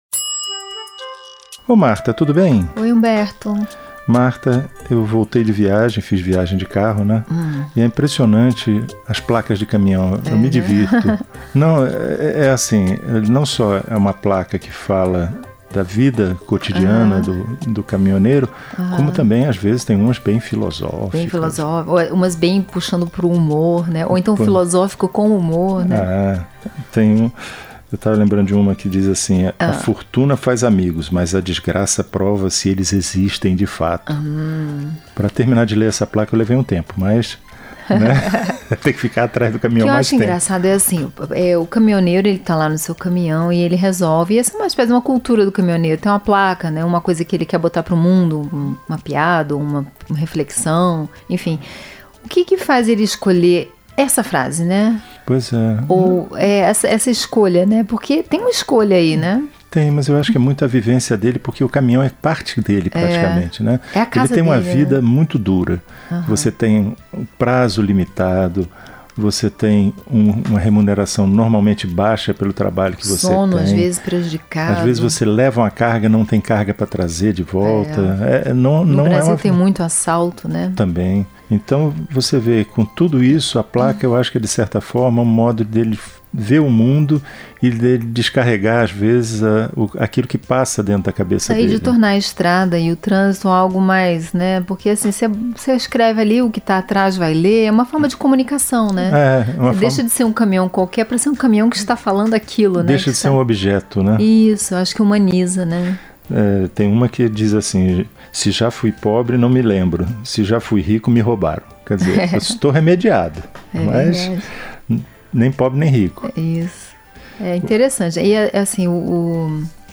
O Conversa de Elevador é um programa de bate-papo sobre temas variados, e que pretende ser ao mesmo tempo leve, breve e divertido, sem deixar de provocar uma reflexão no ouvinte.
Enfim, é uma conversa solta e sem compromisso, marcada pelas experiências do dia-a-dia e pela convivência em um mundo que às vezes parece tão rápido e tão cheio de subidas e descidas quanto um elevador.